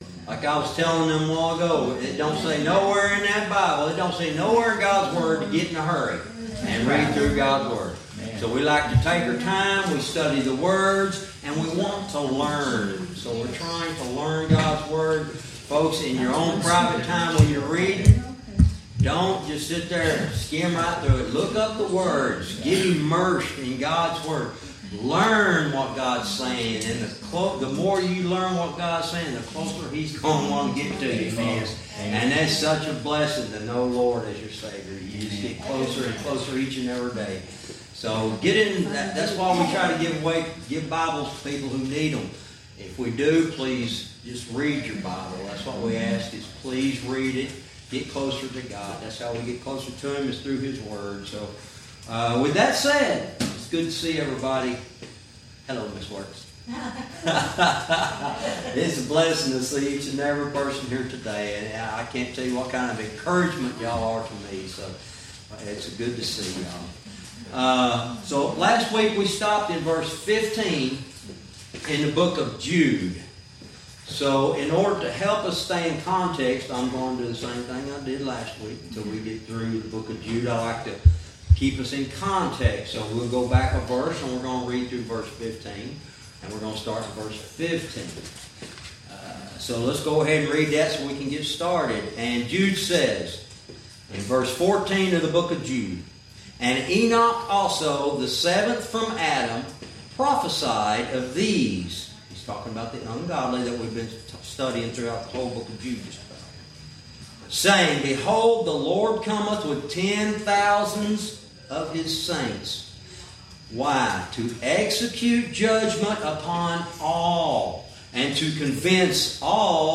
Verse by verse teaching - Jude lesson 69 verse 15